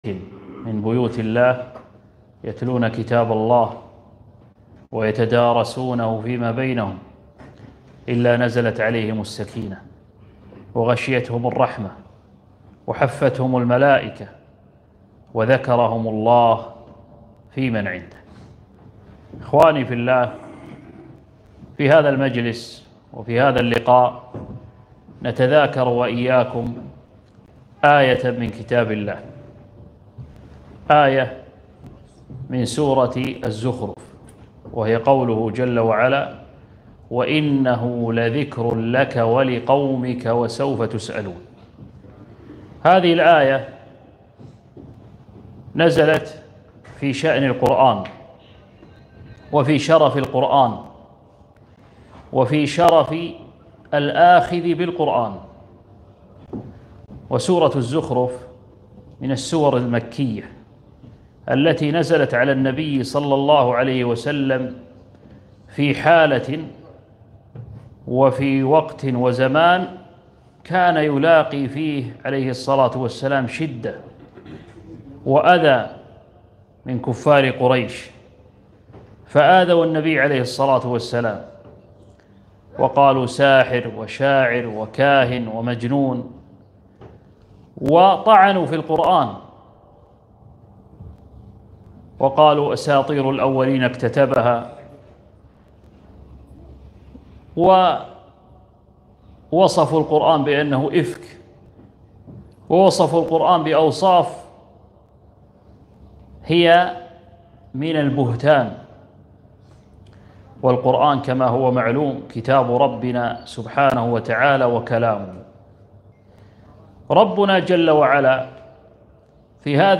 محاضرة - (وإنه لذكر لك ولقومك)